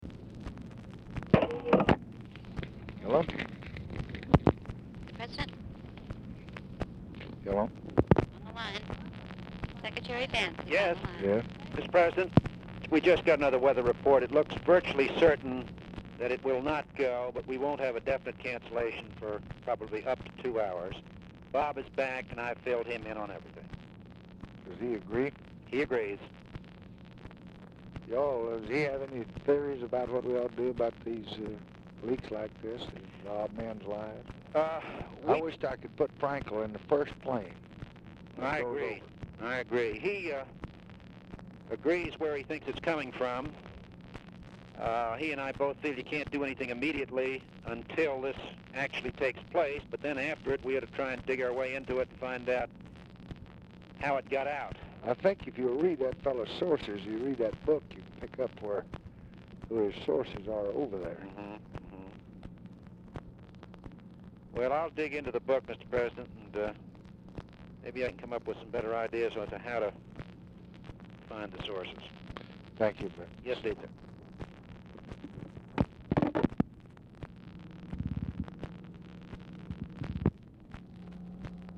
Telephone conversation # 10260, sound recording, LBJ and CYRUS VANCE, 6/24/1966, 7:55PM
Format Dictation belt
Location Of Speaker 1 Oval Office or unknown location